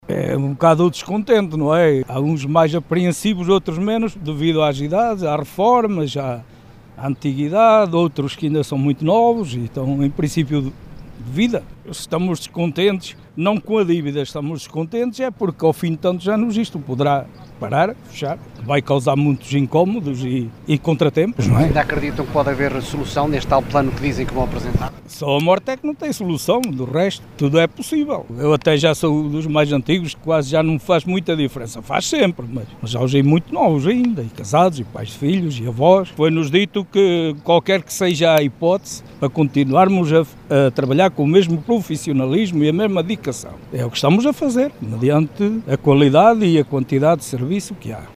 Declarações de um dos 23 trabalhadores do MIC proferidas, esta tarde, após estarem reunidos com dirigentes do SINTAB (sindicato dos trabalhadores da agricultura e das indústrias de alimentação, bebidas e tabacos de Portugal, que acusa as sucessivas administrações desta unidade de abate, divididas entre os municípios de Mirandela e Vila Flor, de serem os “responsáveis pela situação financeira insustentável” que já ultrapassa os 2 milhões de euros, sem esquecer os dirigentes políticos locais, por prometerem constantemente planos de revitalização do complexo, nunca concretizados.
MIC-trabalhador.mp3